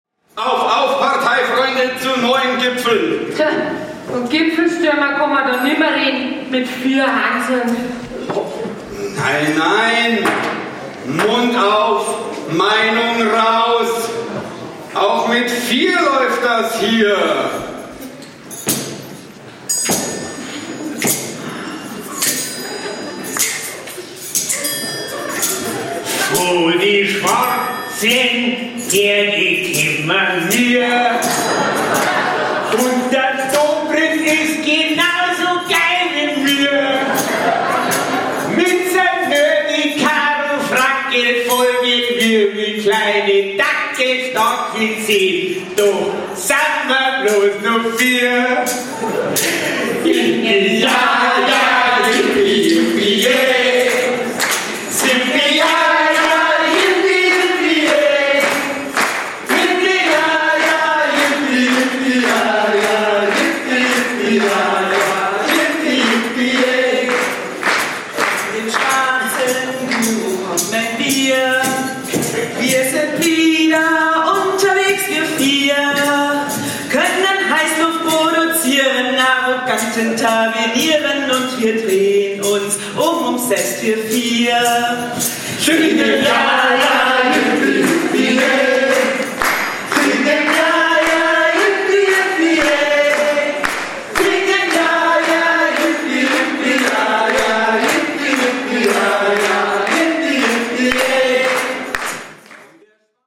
Starkbieranstich
Lied – „Von den Schwarzen Bergen“ – Die CSU-Reste starten durch (Ausschnitt): 1:43 min.